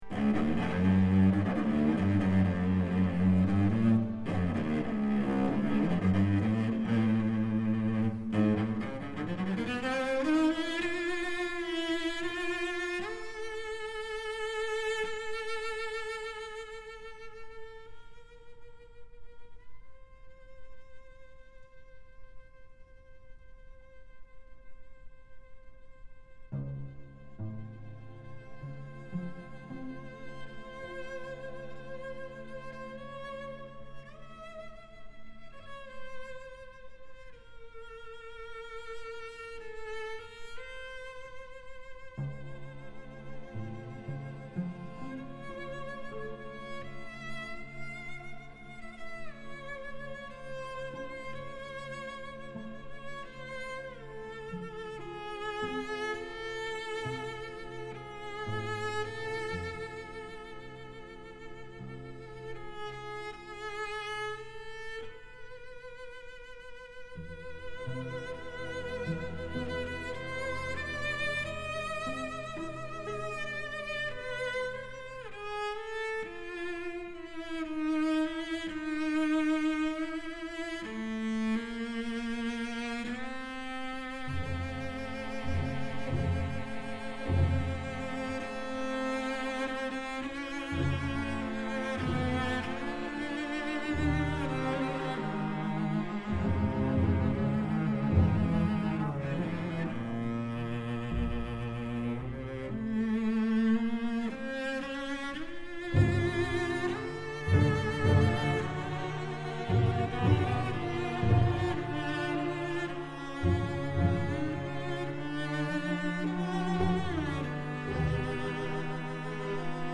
for Solo Violoncello and Strings 為大提琴與弦樂隊而作